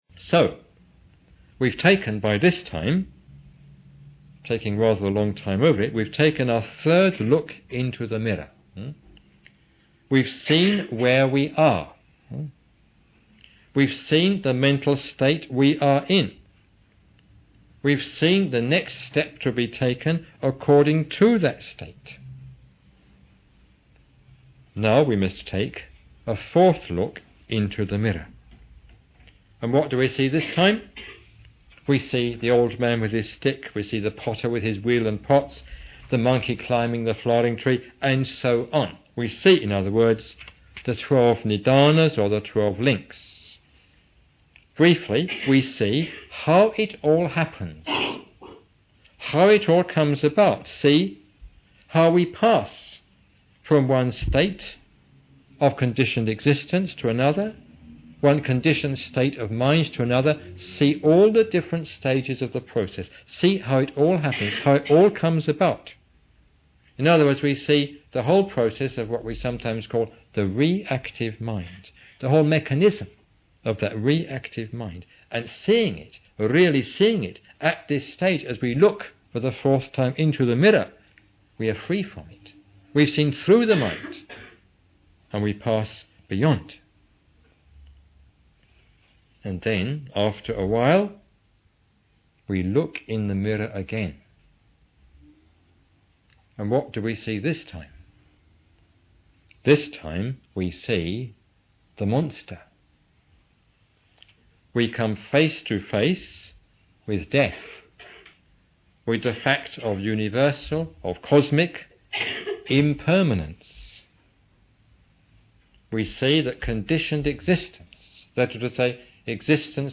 This talk is part of the series Creative Symbols of the Tantric Path to Enlightenment .